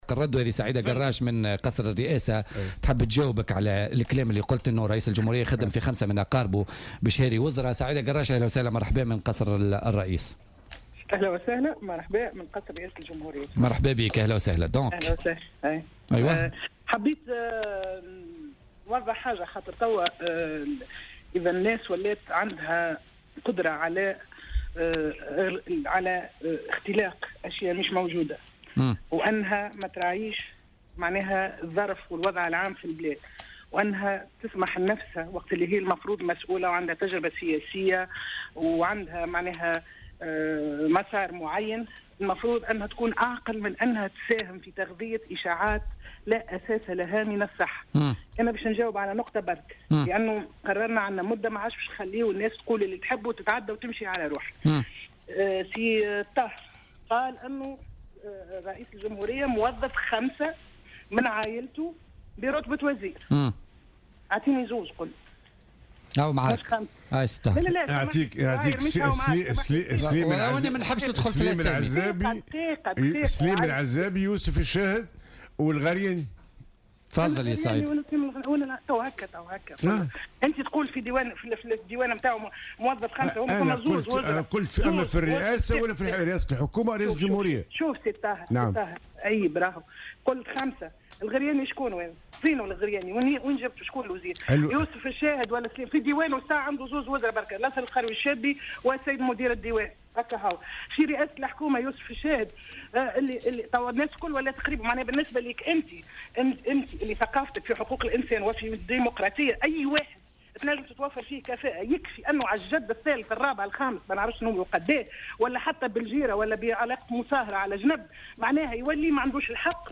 وقالت في اتصال هاتفي ببرنامج "بوليتيكا" على "الجوهرة أف أم" إن من يصرح بمثل هذه الأمور لم يراعي الظرف العام في البلاد.